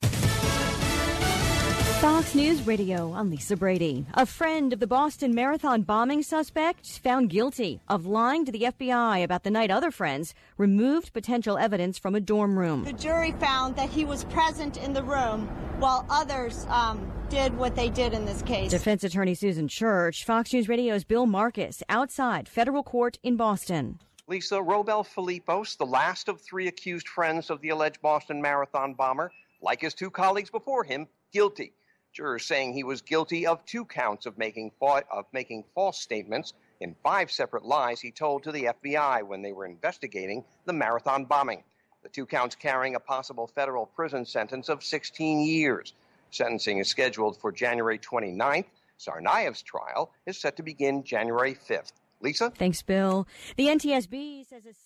(BOSTON) OCT 28 – 12 NOON LIVE FROM OUTSIDE THE FEDERAL COURTHOUSE IN BOSTON….